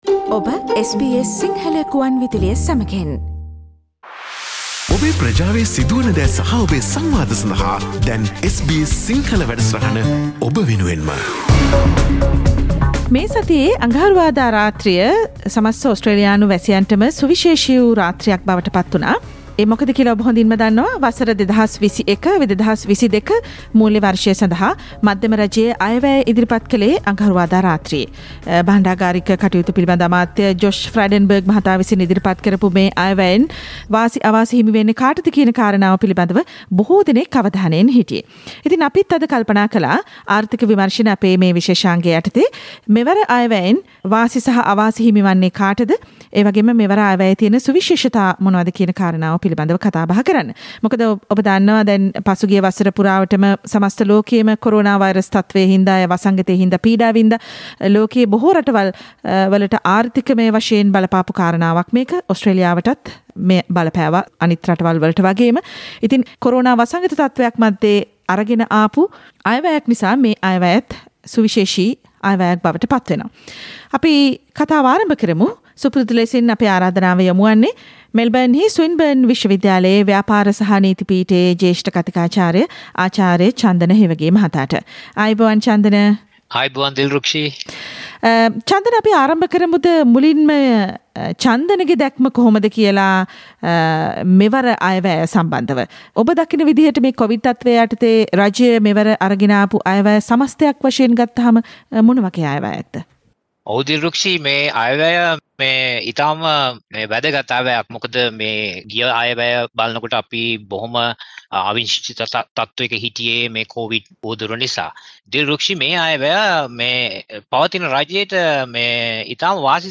what kind of budget did the Australian government bring in for the 2021 - 2022 financial year under the Covid 19 pandemic? Listen to this discussion done by SBS Sinhala Radio